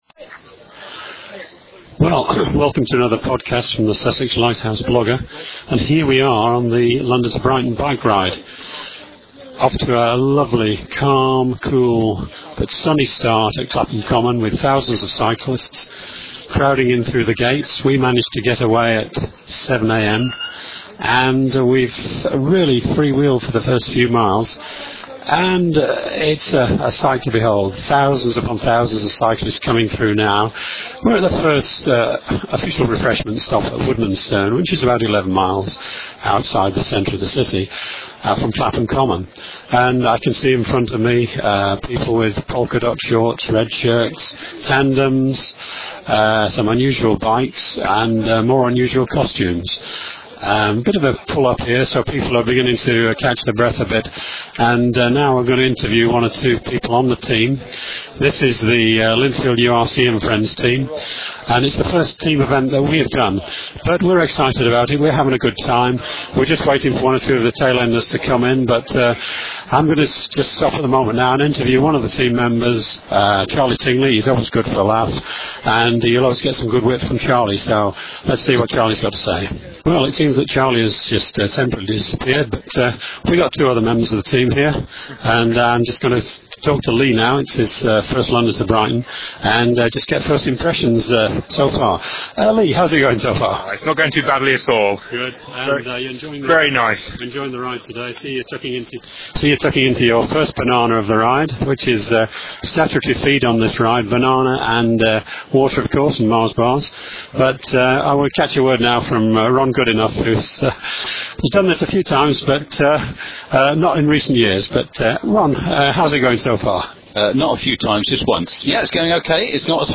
Catch the blab as it happened and follow the guys and gals of the Lindfield URC + Friends team as they pedalled from Clapham Common to the coast in the 2006 London to Brighton Bike Ride.